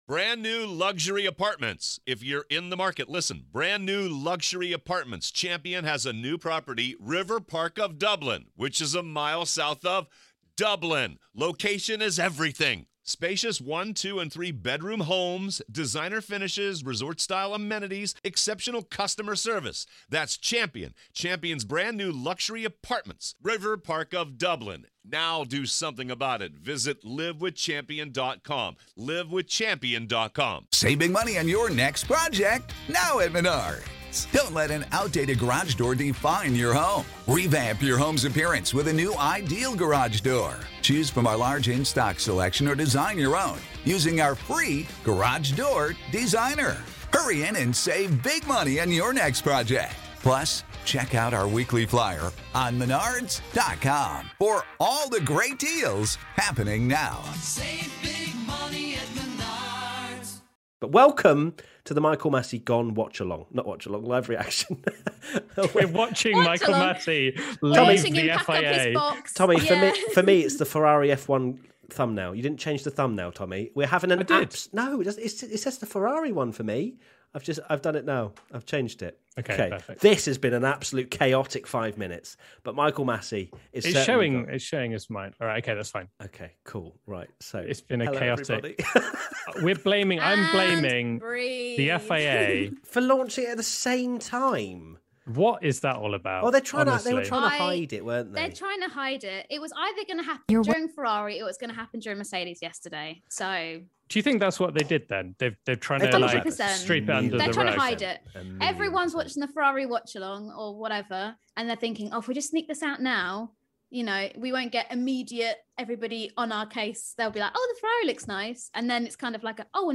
Join us for an unscripted, unplanned chat reacting to breaking news that Michael Masi was been removed as FIA Race Director for the 2022 F1 season.